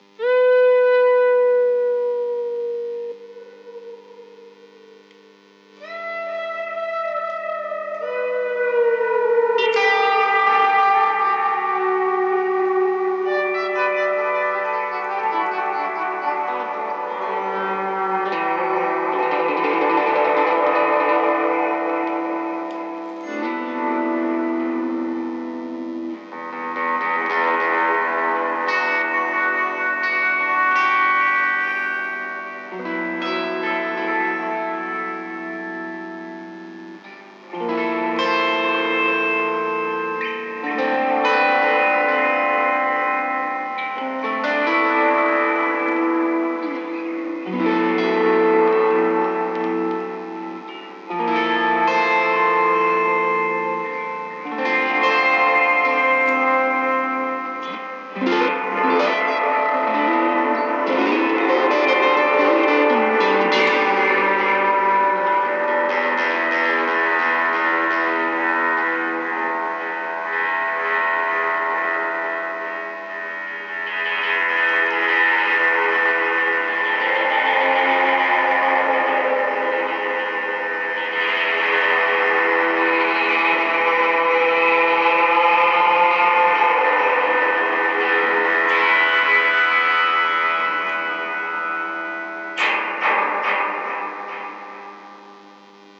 本物のスプリングリヴァーブに加え、新しくおもしろい事が可能なモード。ディレイのように感じますが、それとはまるで違います。霧の中というイメージです。途中dryつまみをいじってますが、dry音下げた使い方も好きですね。どんどん異空間に落ちていくみたいで、リバーヴ音だけ聴いてても気持ちがいい。
• dry = 7時
LIGHT-PEDAL_REFLECT.wav